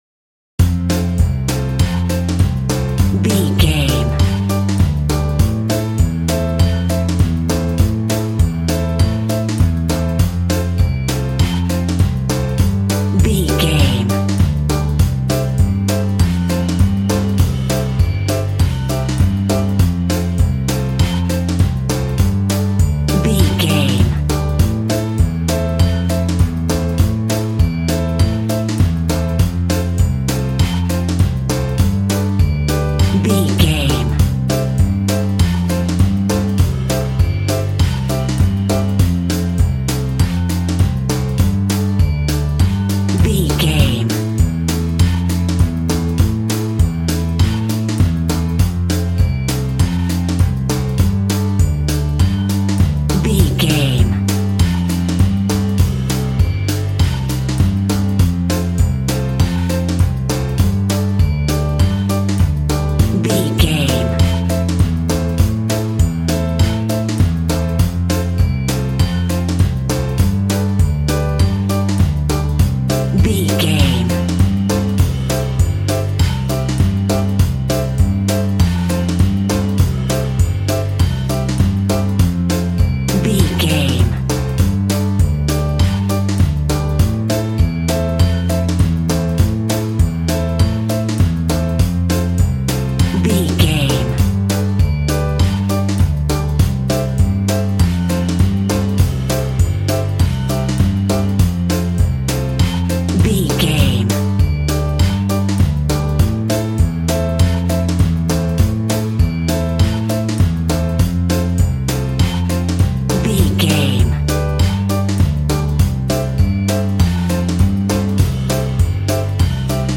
Ionian/Major
kids instrumentals
childlike
cute
happy
kids piano